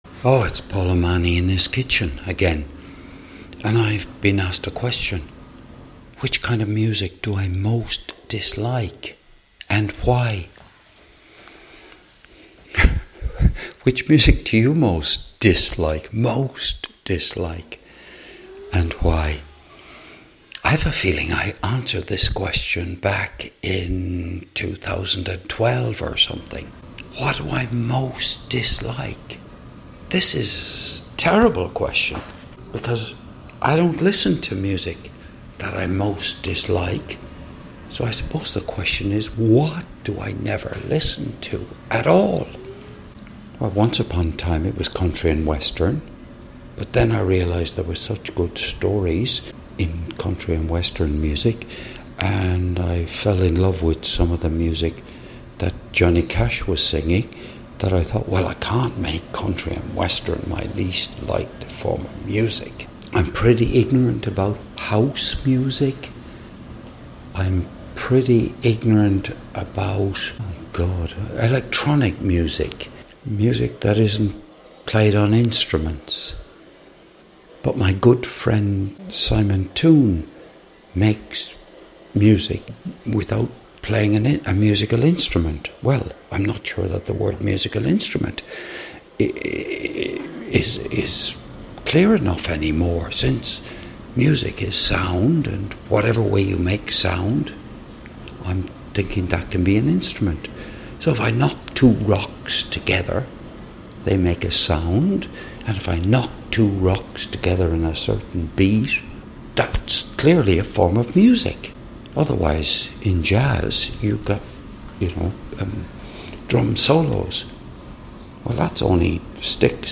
This was recorded partly during the day and partly at midnight on the 6th of October 2025.